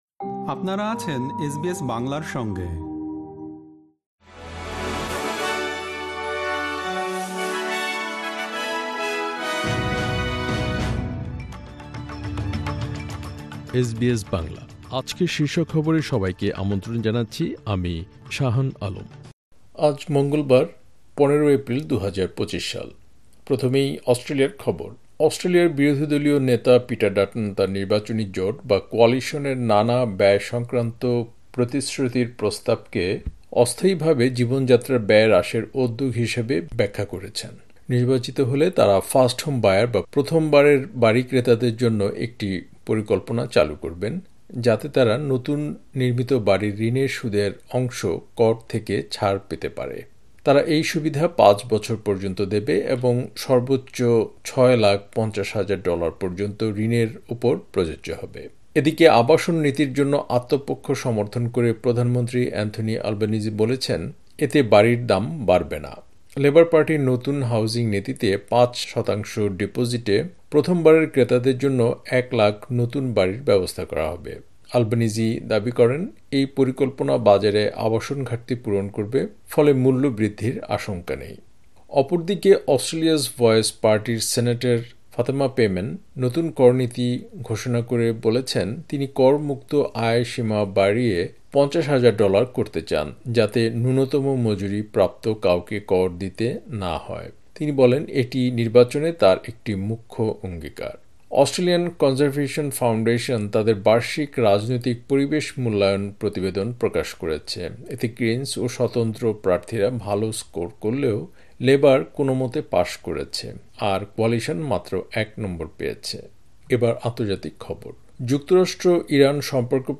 এসবিএস বাংলা শীর্ষ খবর: ১৫ এপ্রিল, ২০২৫